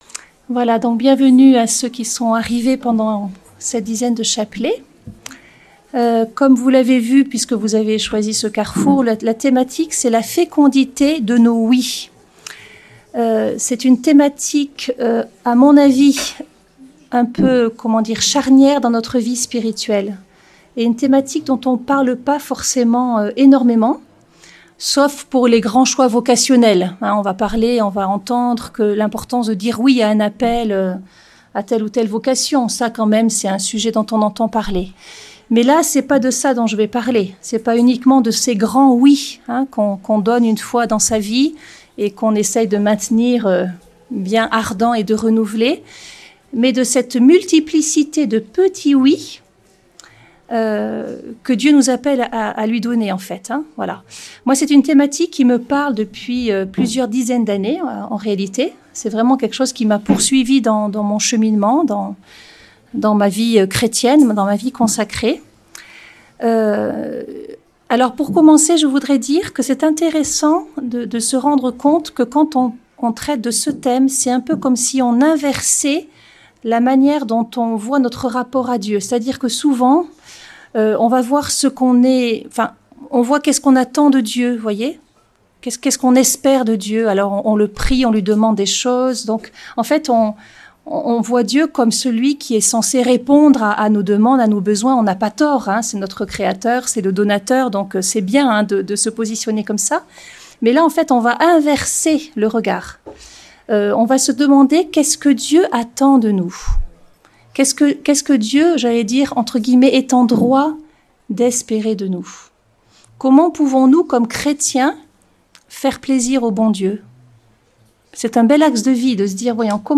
Lourdes, Pèlerinage avec la Cté des Béatitudes